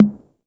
• Low Mid Tom G# Key 08.wav
Royality free tom sound tuned to the G# note. Loudest frequency: 258Hz
low-mid-tom-g-sharp-key-08-f6L.wav